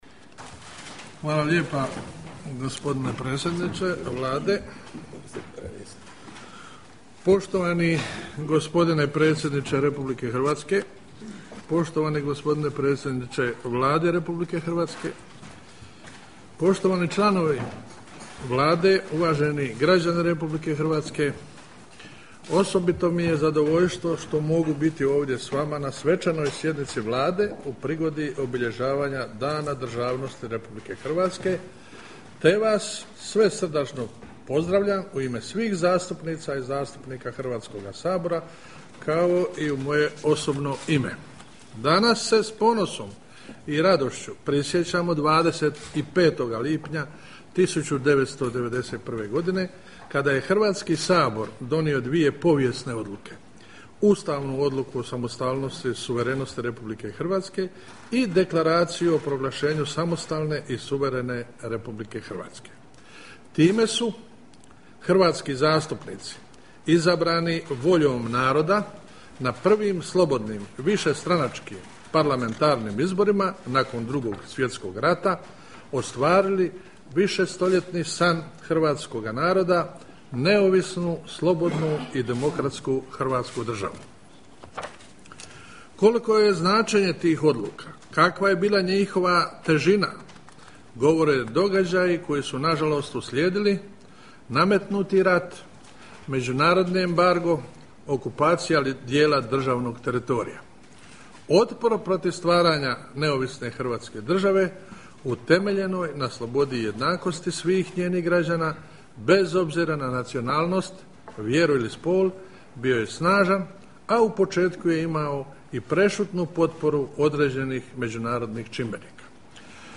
Svečana sjednica Vlade Republike Hrvatske u povodu Dana državnosti
Govor predsjednika Hrvatskog sabora Luke Bebića